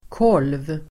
Uttal: [kål:v]